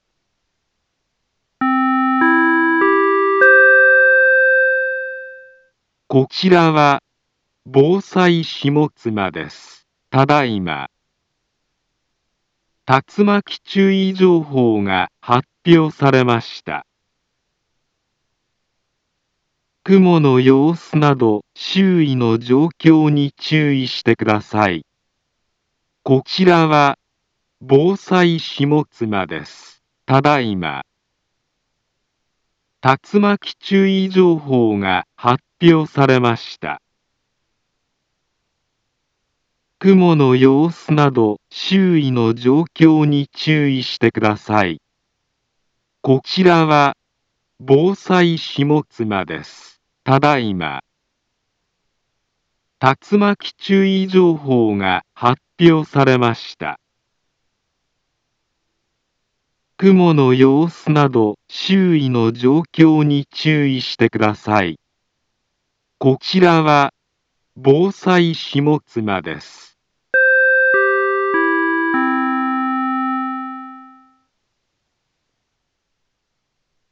Back Home Ｊアラート情報 音声放送 再生 災害情報 カテゴリ：J-ALERT 登録日時：2022-06-03 15:19:42 インフォメーション：茨城県南部は、竜巻などの激しい突風が発生しやすい気象状況になっています。